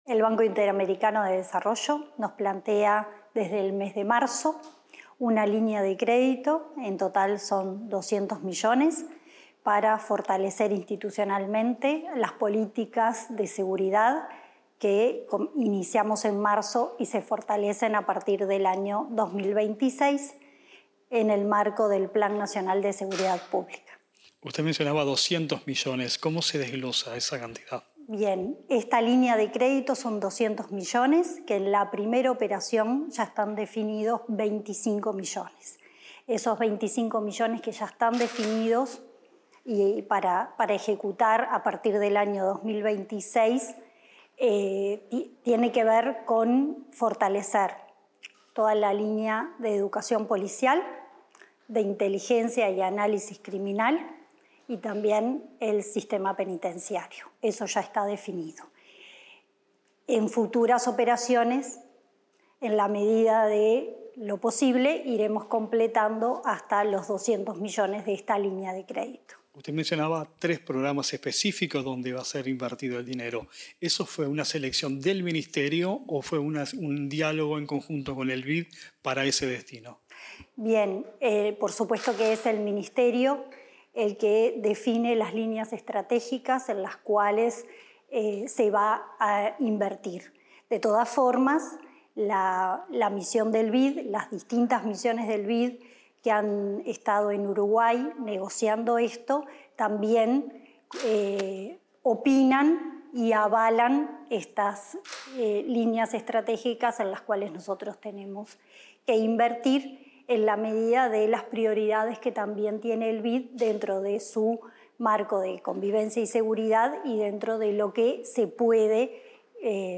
Declaraciones de la subsecretaria del Ministerio del Interior, Gabriela Valverde